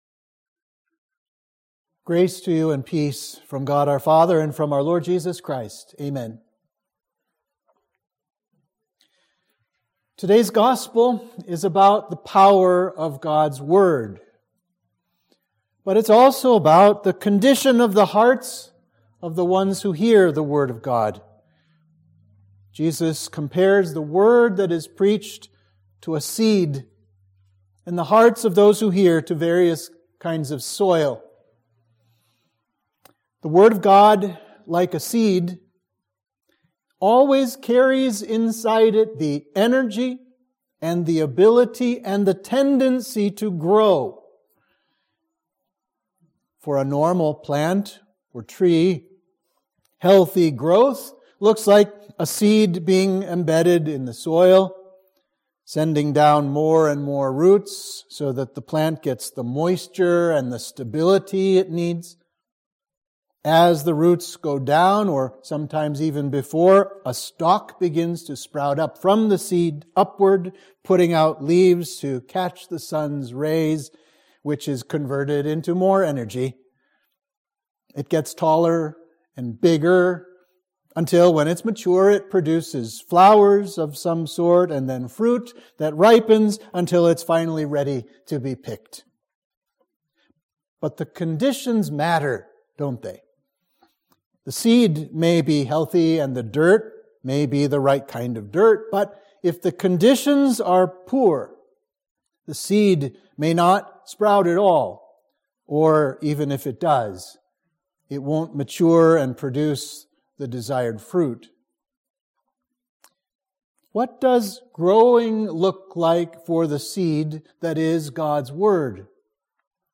Sermon for Sexagesima